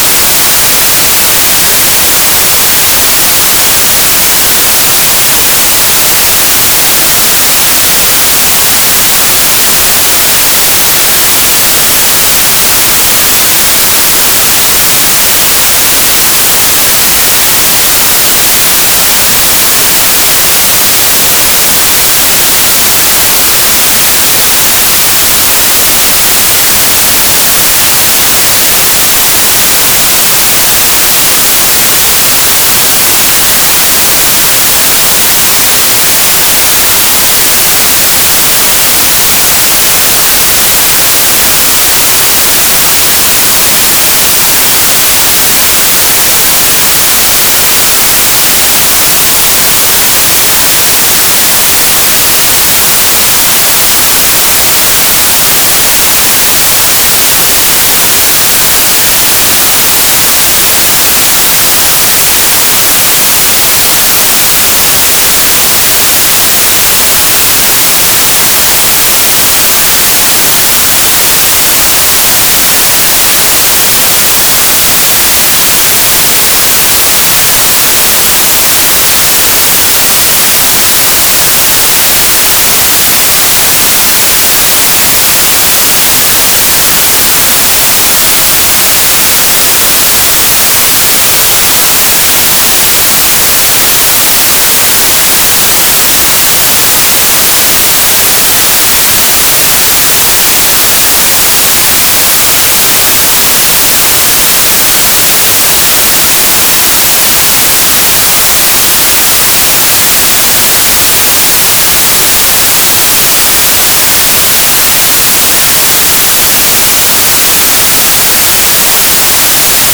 whitenoise.wav